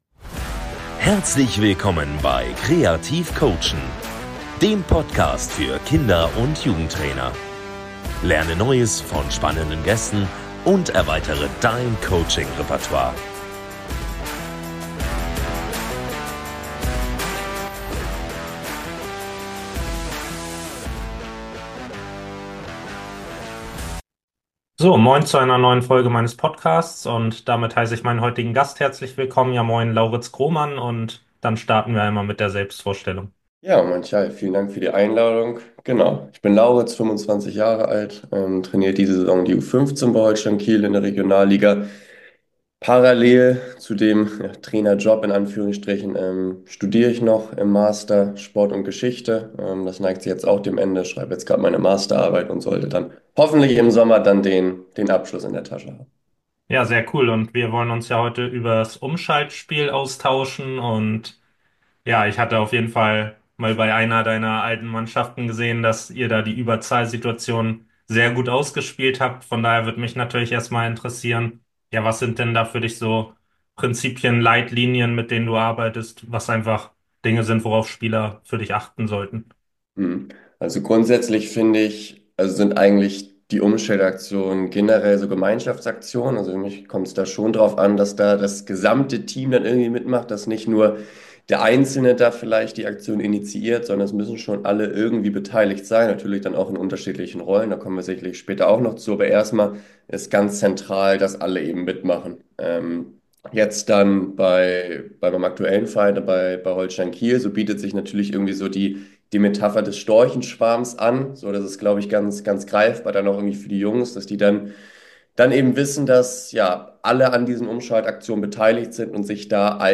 Hier hörst du verschiedenste Fachleute zu unterschiedlichen Themen rund um den Kinder- und Jugendfußball. Ziel ist es, dass du mit Hilfe des Podcasts dein Coaching-Reportoire erweitern kannst.